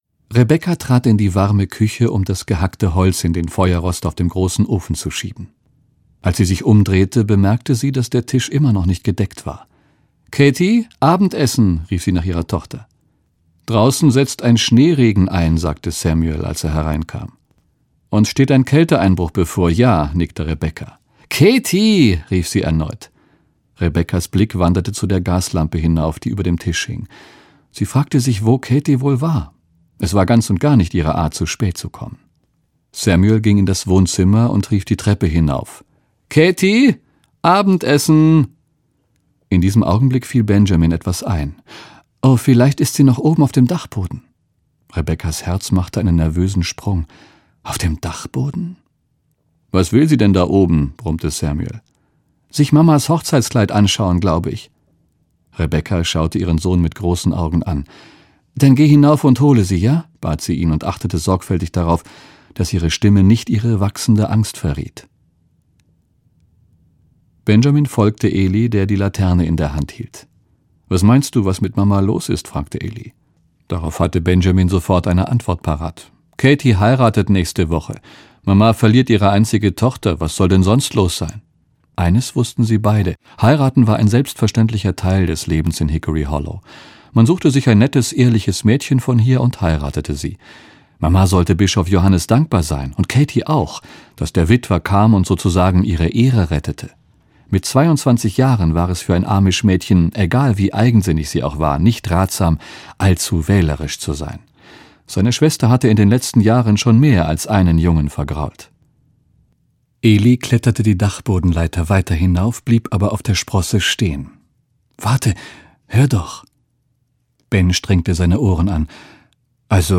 Was auch geschehen mag - Beverly Lewis - Hörbuch